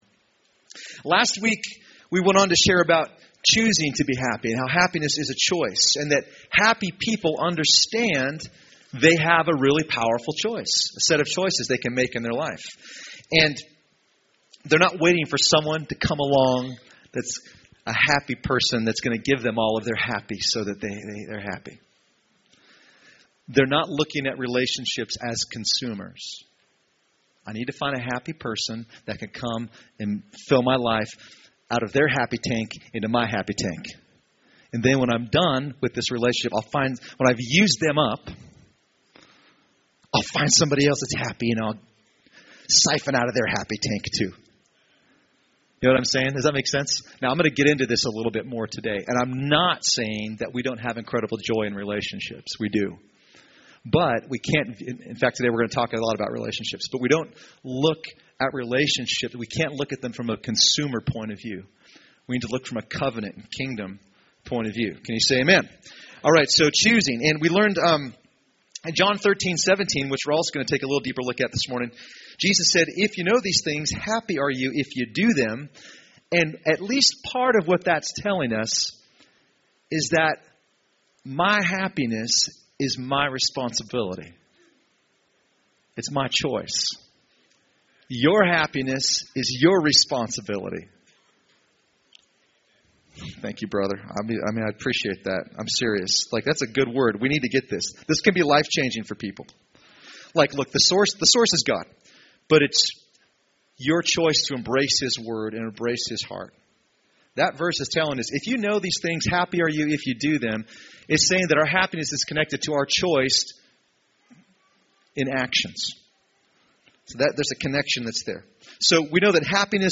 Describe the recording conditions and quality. Recorded at New Life Christian Center, Sunday, February 15, 2015 at 11AM.